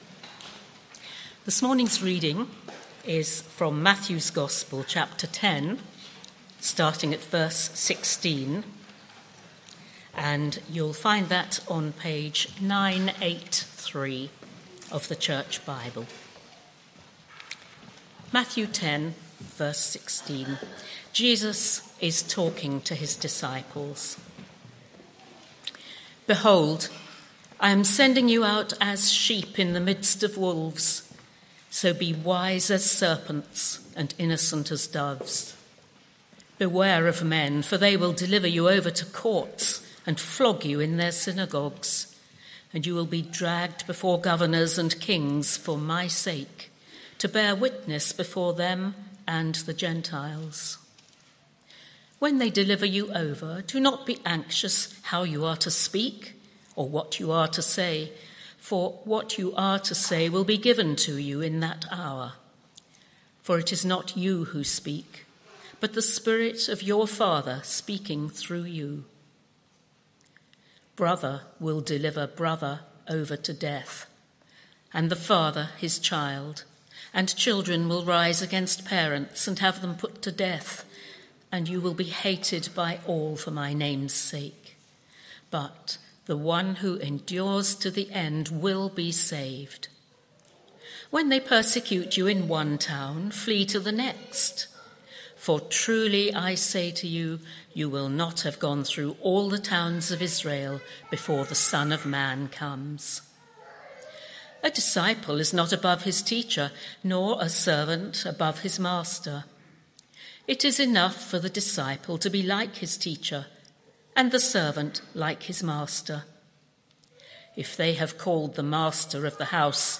Media for Morning Meeting on Sun 29th Dec 2024 10:30 Speaker
AM Theme: Why did Jesus come? Sermon - Audio Only Search media library...